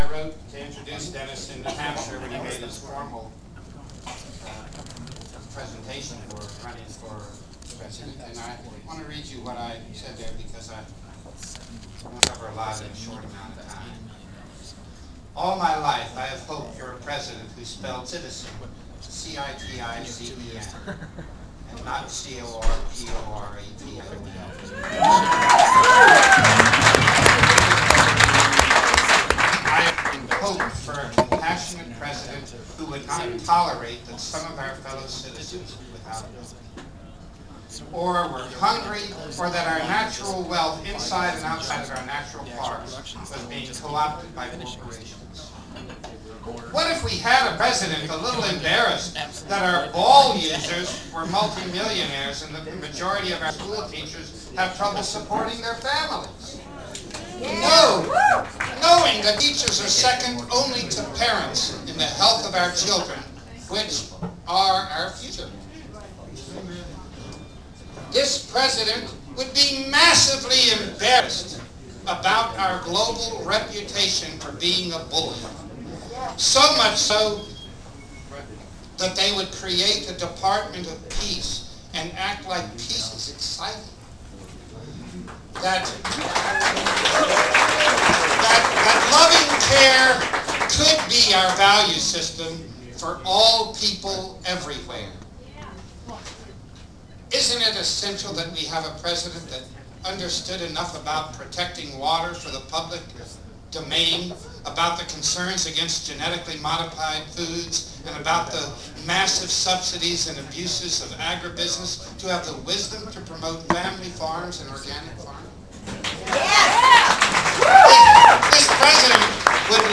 Pictures I took at the Kucinich Campaign Party in Washington, DC. plus audio files (.wav)